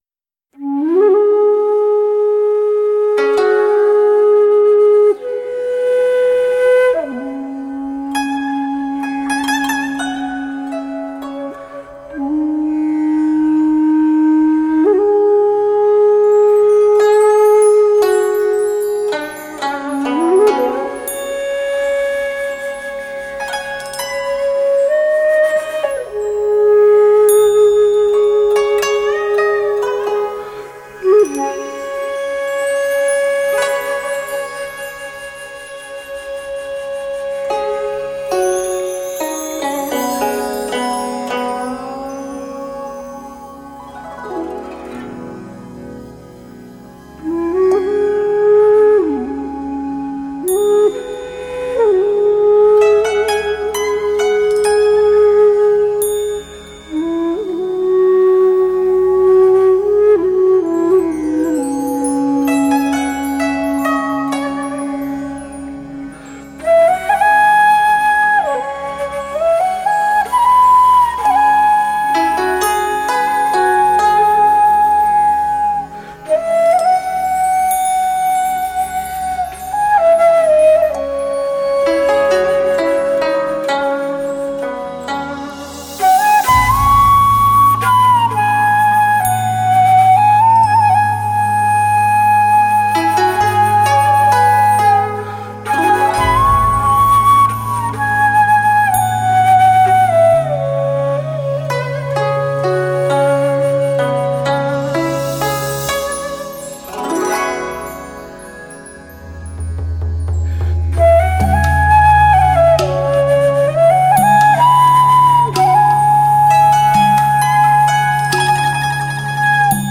东方禅意音乐
古琴
南箫/班苏里
更增添了除中国的传统乐曲古琴和竹笛以外的南箫等各类竹箫类器乐。